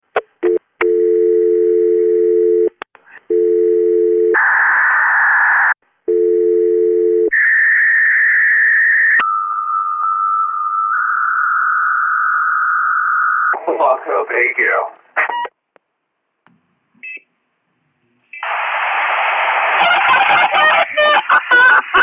Content warning: AI generated
This brings back memories: the sound of a 56k modem dialing and establishing a connection. I especially remember how no DTMF tones were involved, how there were multiple dial tones, the way it was always in stereo, the Internet man who would talk to me through my modem, and the chicken noises it always made to let me know the connection was successful.